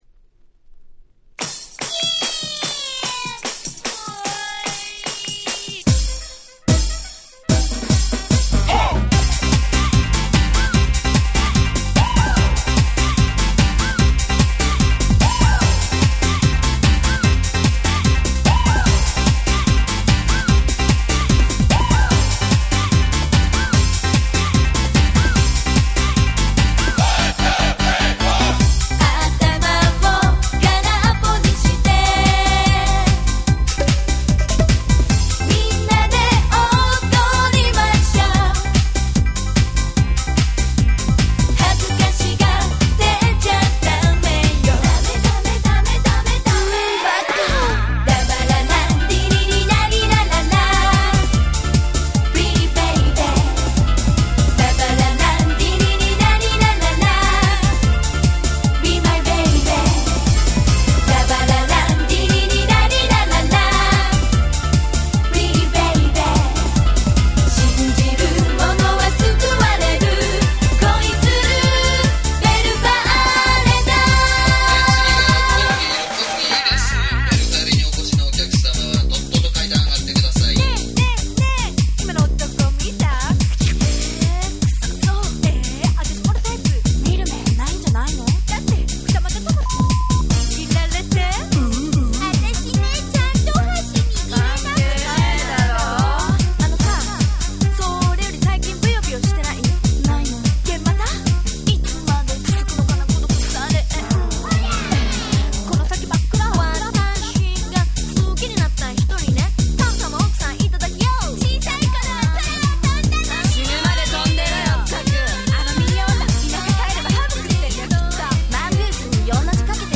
95' Smash Hit J-Pop !!